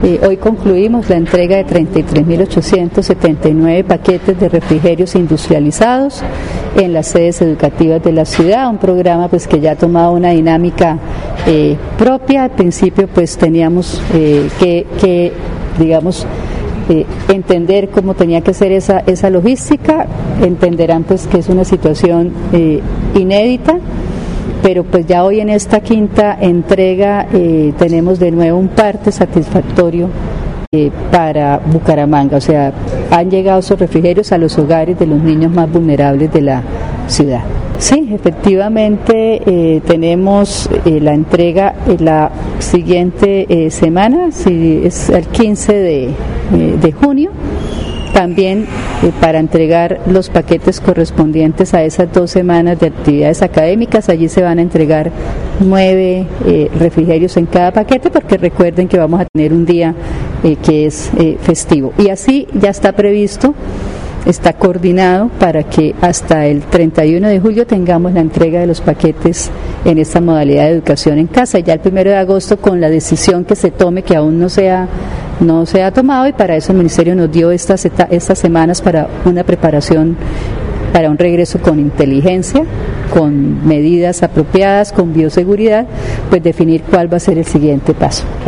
Ana Leonor Rueda Vivas, secretaria de Educación Bucaramanga